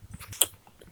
Sonidos sobrenaturales
Como los chasquidos duran una fracción de segundo y me pillan por sorpresa, le pedí al Señor en oración que me permitiera grabar alguno, pero cuando dejaba el micrófono abierto para grabar, no sonaba ninguno (el Señor en su infinito Amor lo que siempre hace es sorprendernos, como es lógico, las cosas son cuando Él quiere y no cuando nosotros esperamos).
Después descargué el audio en el ordenador y corté exclusivamente el trozo donde se oye y lo comparto con ustedes, para que así puedan experimentar otra de las incesantes muestras de su Amor infinito hacia nosotros.
Chasquido-sobrenatural-de-los-dedos-del-Senor.mp3